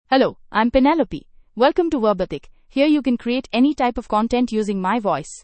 Penelope — Female English (India) AI Voice | TTS, Voice Cloning & Video | Verbatik AI
FemaleEnglish (India)
Voice sample
Female
Penelope delivers clear pronunciation with authentic India English intonation, making your content sound professionally produced.